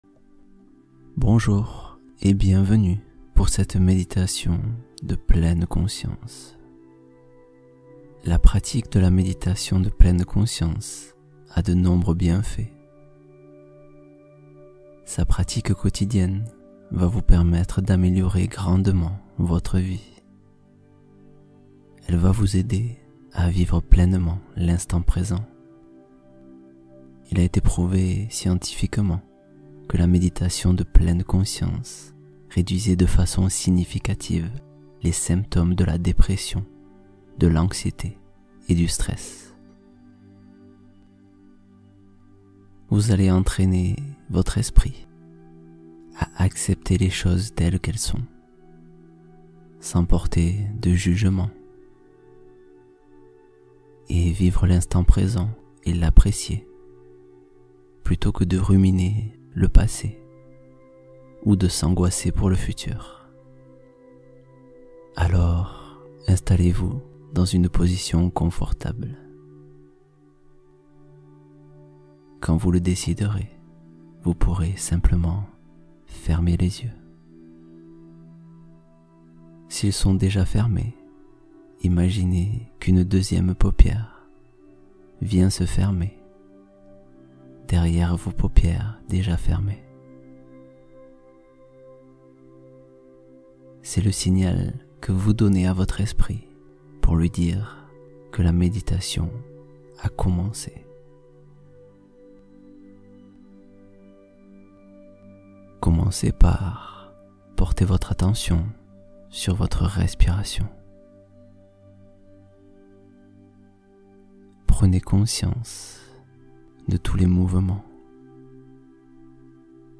Se détacher du passé — Hypnose pour explorer de nouveaux repères